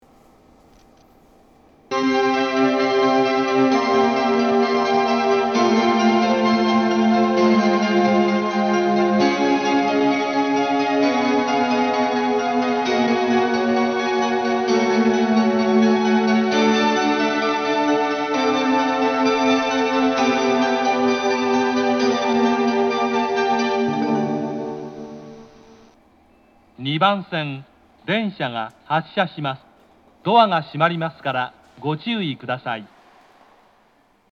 発車メロディー
余韻切りです。